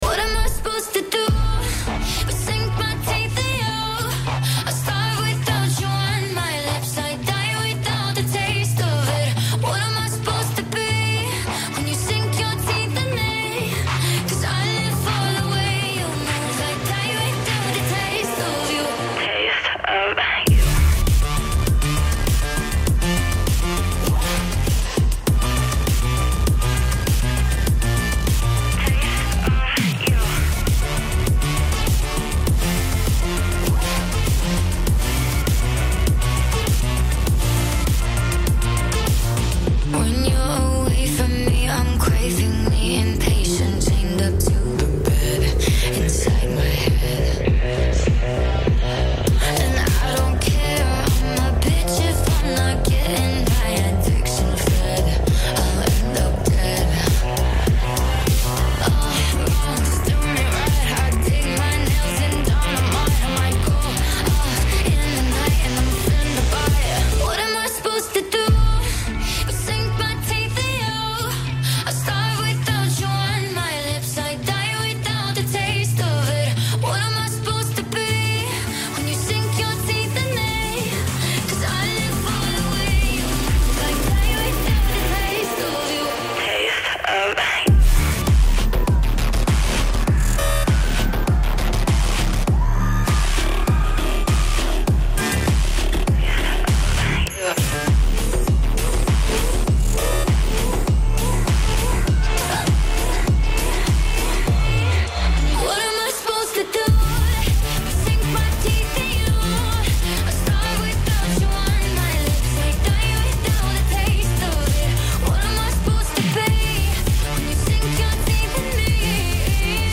Also find other EDM Livesets, DJ Mixes and